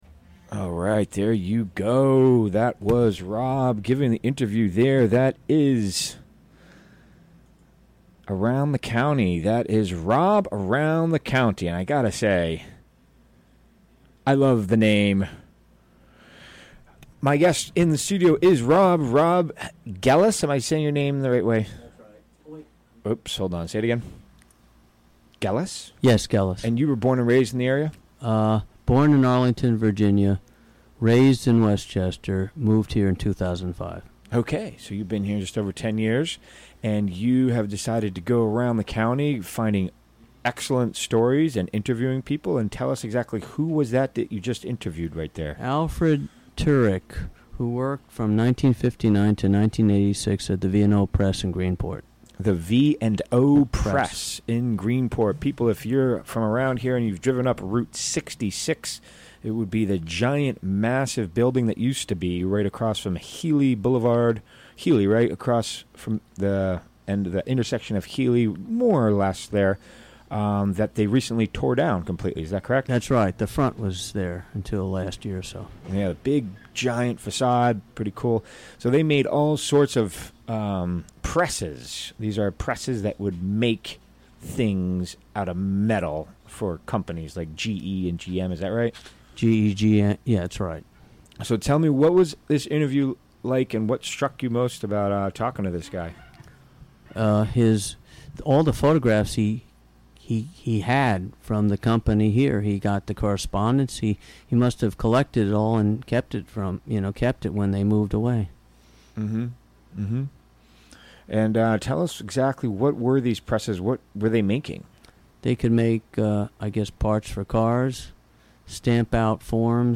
Recorded during the WGXC Afternoon Show Monday, September 25, 2017.